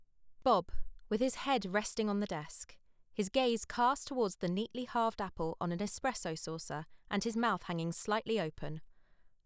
I’m attaching a part that I think is particularly bad with clicks on the words “desk” “Gaze” and “neatly” and a slight buzz at the end of the word “Bob.”
My narrator is sipping water regularly to avoid mouth noise but these clicks and artefacts keep cropping up.
Generally, I think you’re recording with too little gain. Currently it’s peaking at -17dB and you’d be better off aiming for a peak around -10 to -6.